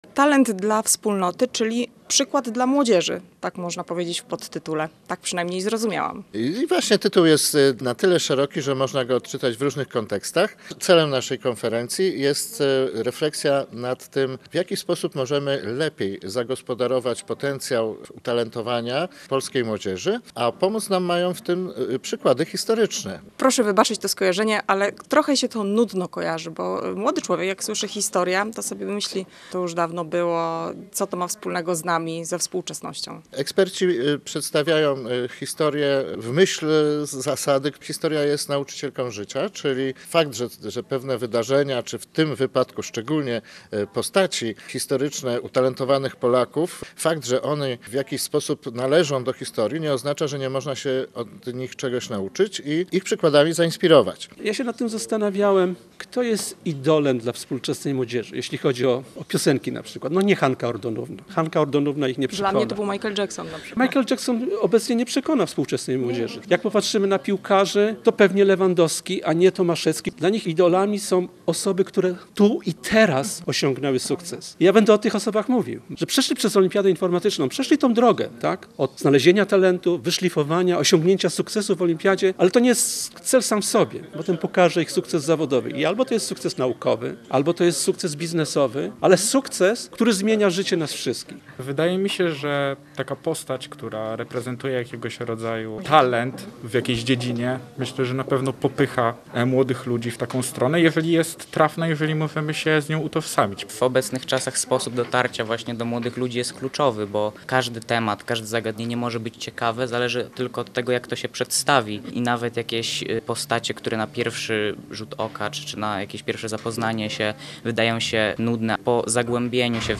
Posłuchaj materiału dziennikarki Radia Gdańsk: